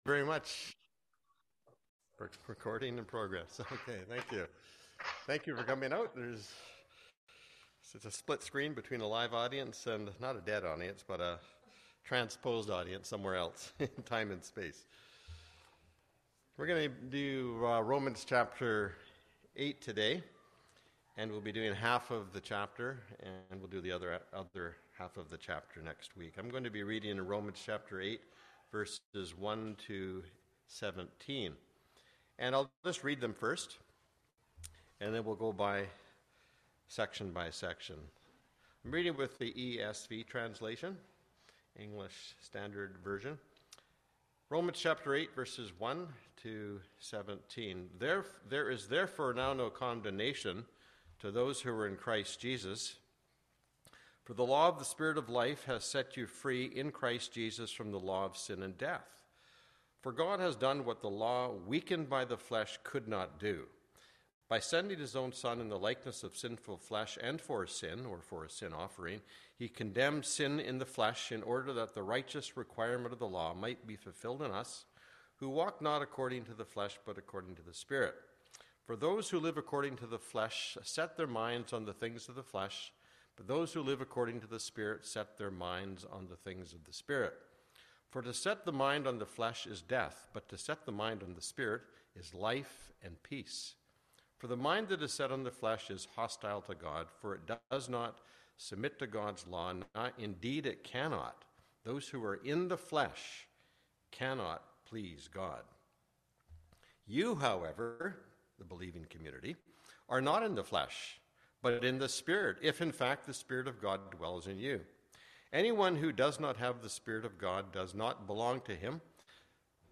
Romans Sermons Click on the title to play in your player / Right-click on the title and click “save as…” to download to your computer.